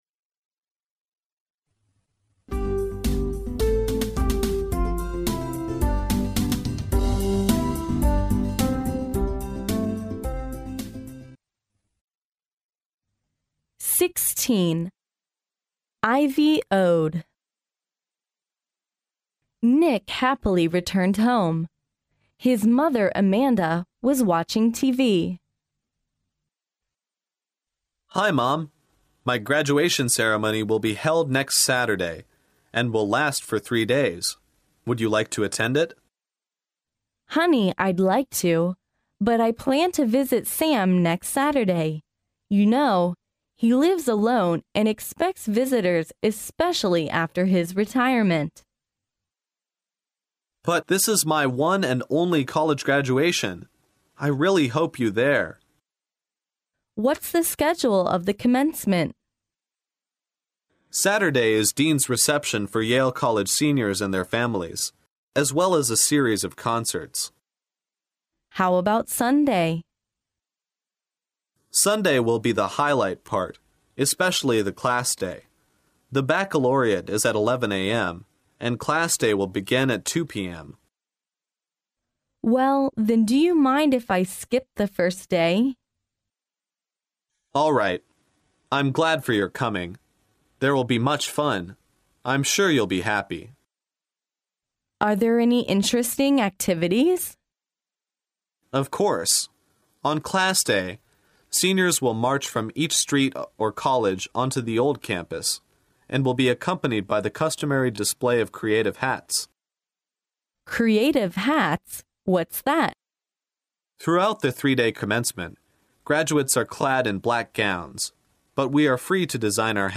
耶鲁大学校园英语情景对话16：常青颂（mp3+中英）